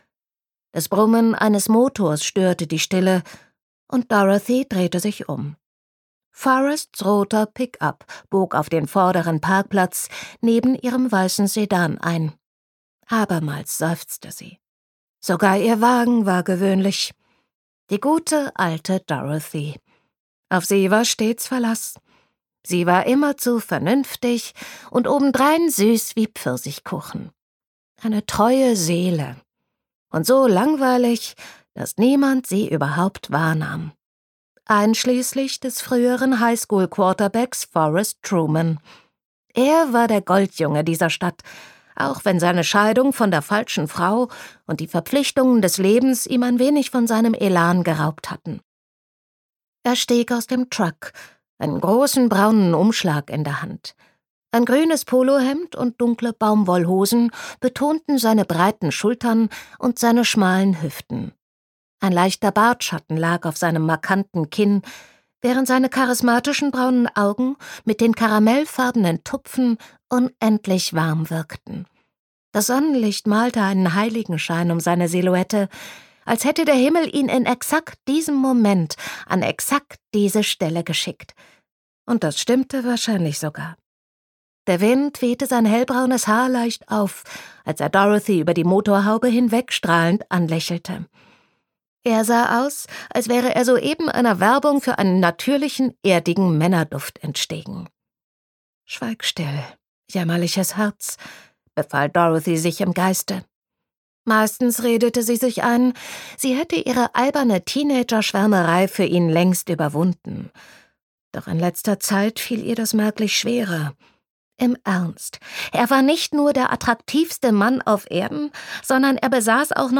Gekürzt Autorisierte, d.h. von Autor:innen und / oder Verlagen freigegebene, bearbeitete Fassung.
Switch Studio, Berlin, 2022/ argon verlag